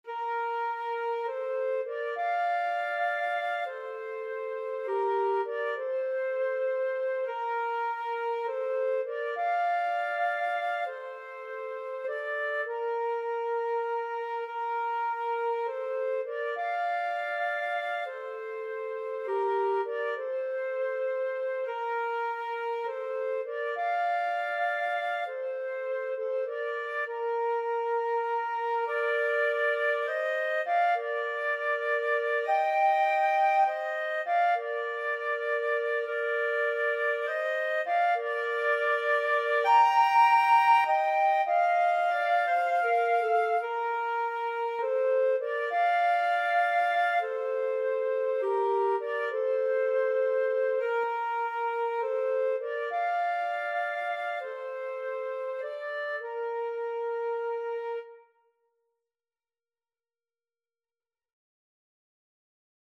Free Sheet music for Flute-Clarinet Duet
FluteClarinet
3/4 (View more 3/4 Music)
Bb major (Sounding Pitch) C major (Clarinet in Bb) (View more Bb major Music for Flute-Clarinet Duet )
Gently. In the manner of a lullaby ( = c. 100)
Traditional (View more Traditional Flute-Clarinet Duet Music)